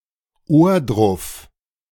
Ohrdruf (German: [ˈoːɐ̯ˌdʁʊf]
De-Ohrdruf.ogg.mp3